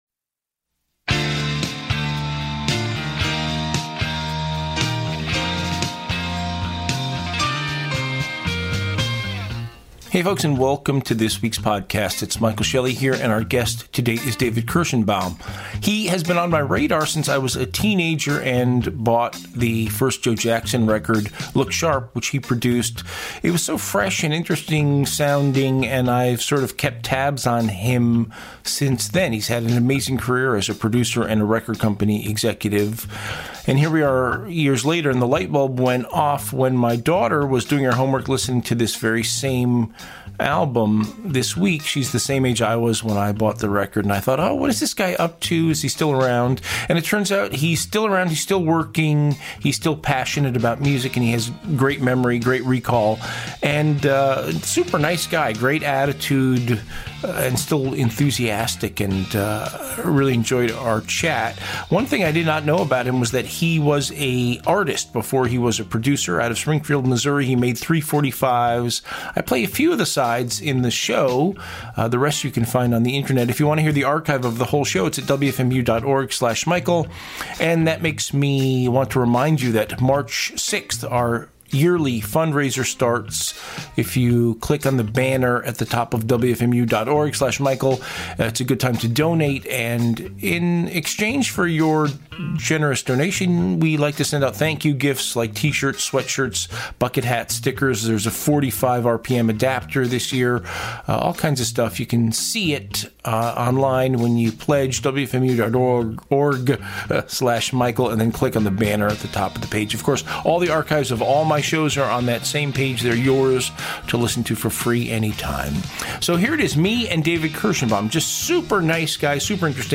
"Interview"https